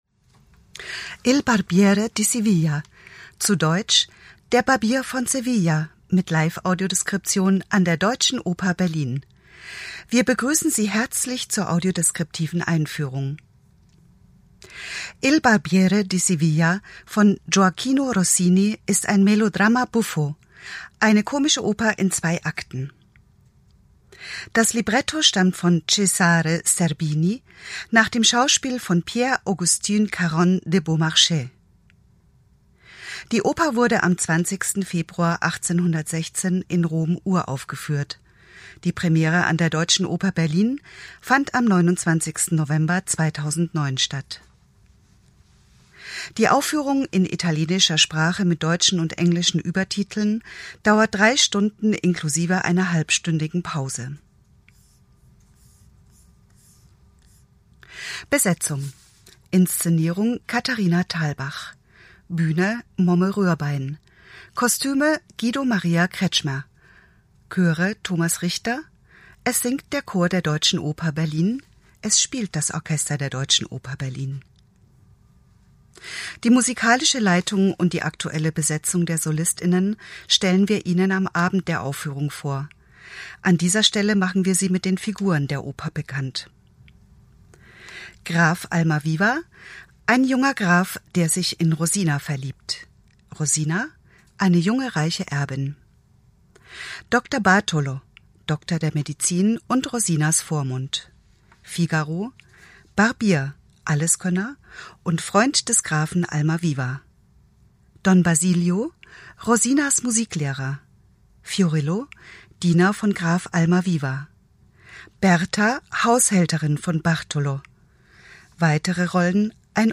Audiodeskriptive Einführung zu Der Barbier von Sevilla